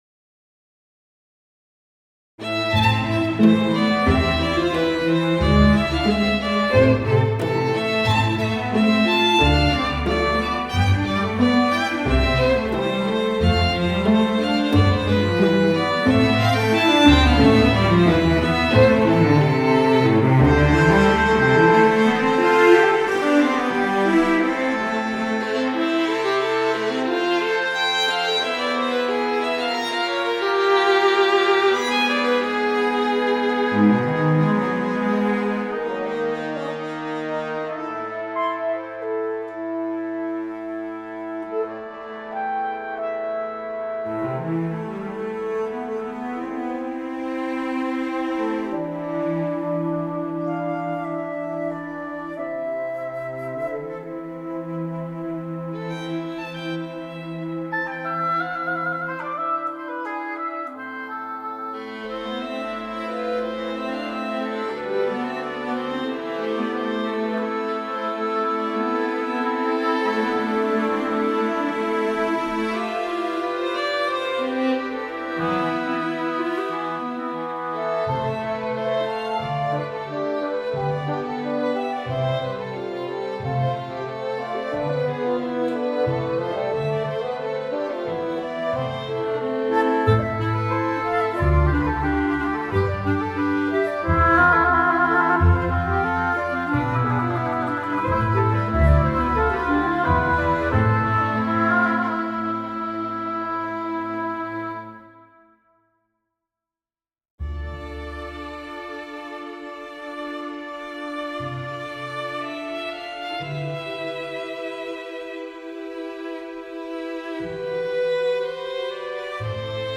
Mini-concerto for violin